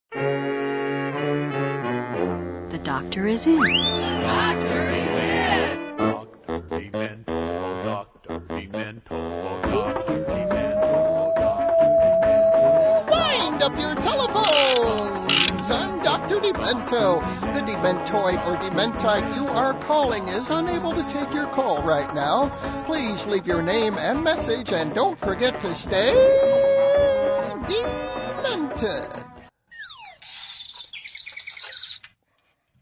Dr. Demento's Telephone Greeting
DrDemento_PhoneGreeting.mp3